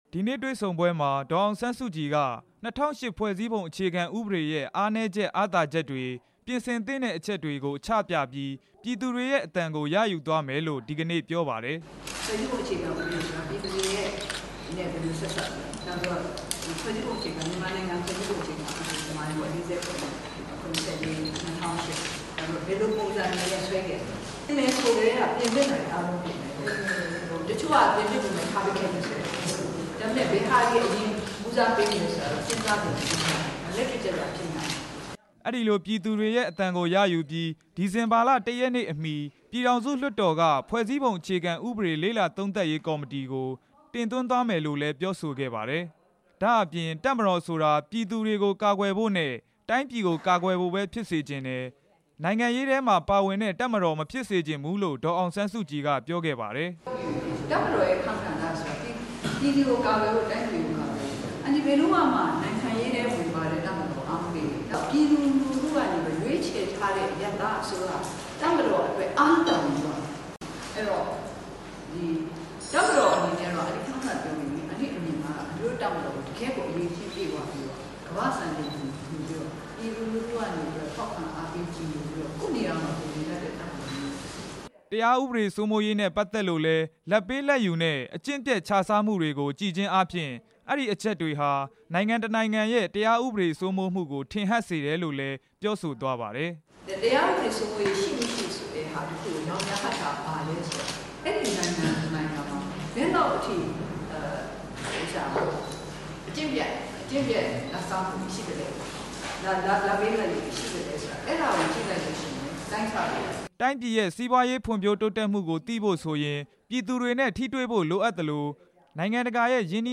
ဒေါ်အောင်ဆန်းစုကြည်ရဲ့ ပြောကြားချက်များ
နေပြည်တော် လွှတ်တော်ဝင်းအတွင်းမှာရှိတဲ့ တရားဥပဒေစိုးမိုးရေးနဲ့ တည်ငြိမ်အေးချမ်းရေး ကော်မ တီရေးရာအဆောင်မှာ  ဒီနေ့ နေ့လည်ပိုင်းက သတင်းထောက်တွေနဲ့ တွေ့ဆုံစဉ် ဒေါ်အောင်ဆန်း စုကြည်က အခုလို တိုက်တွန်းပြောဆိုလိုက်တာပါ။